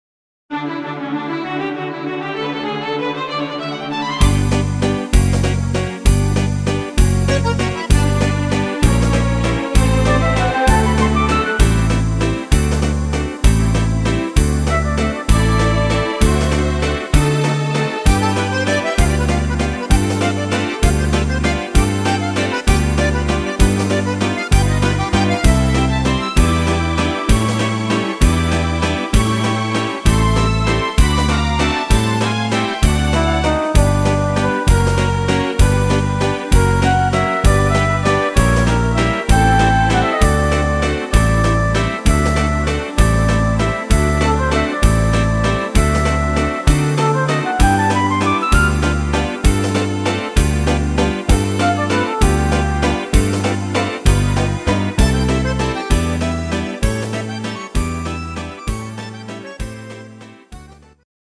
Musette Walzer